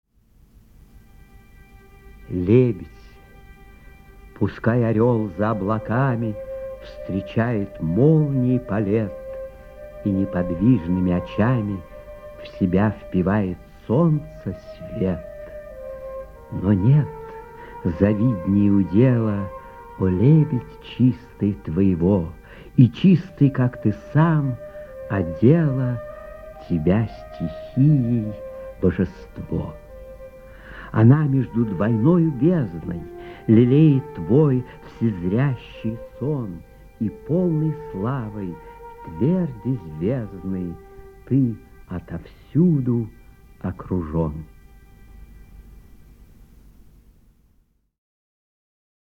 1. «Ф. Тютчев – Лебедь (читает Владимир Зельдин)» /
Tyutchev-Lebed-chitaet-Vladimir-Zeldin-stih-club-ru.mp3